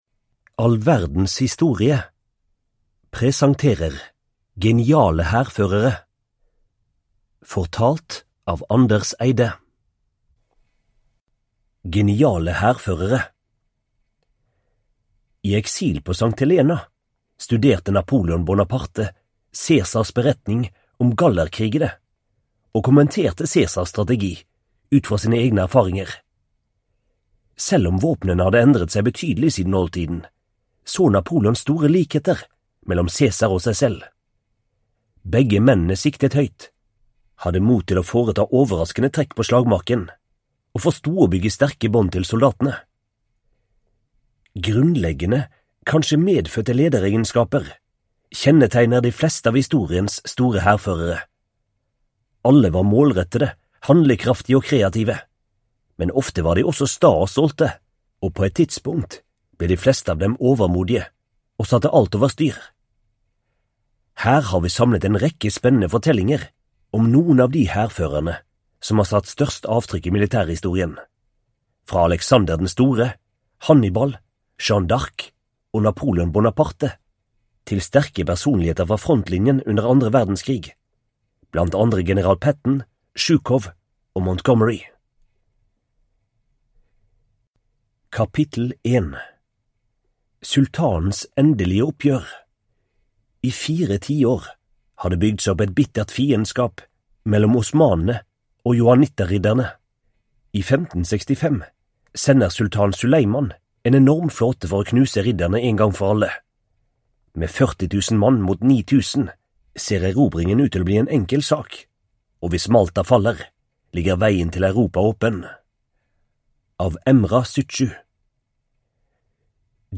Geniale hærførere (ljudbok) av All Verdens Historie